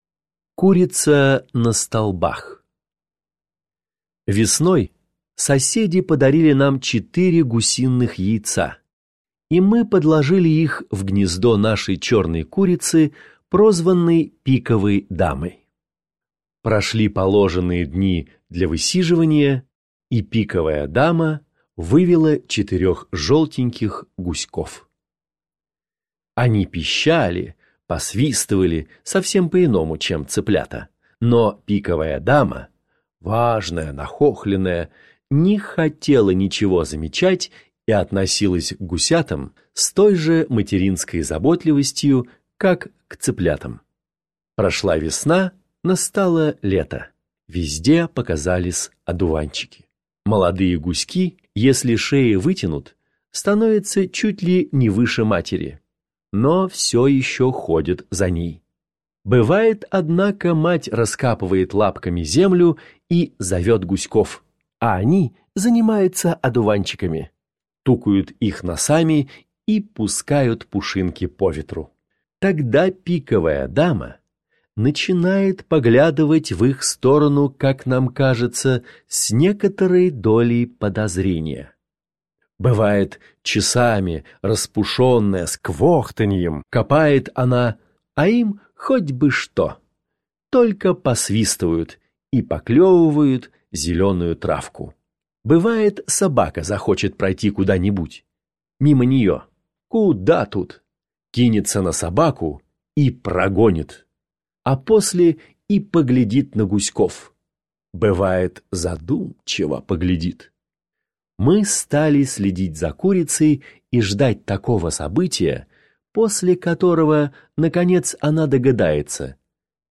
Курица на столбах – Пришвин М.М. (аудиоверсия)